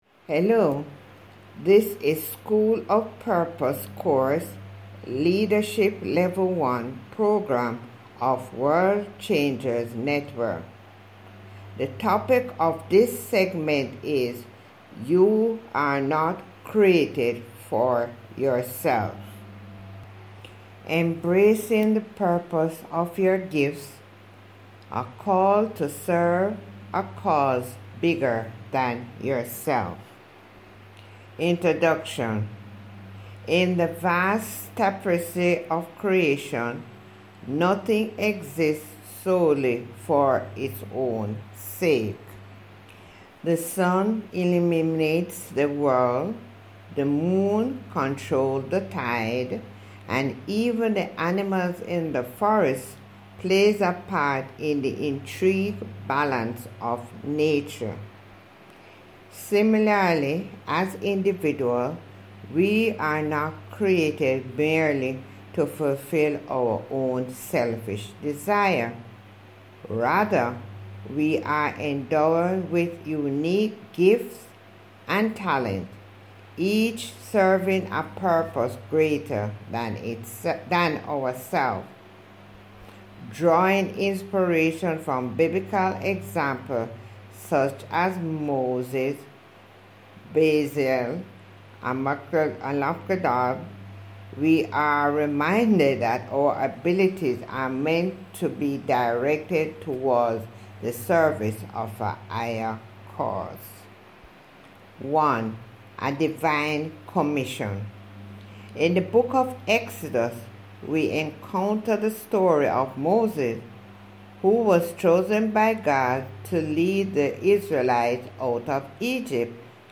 Listen to Human Voice Reading: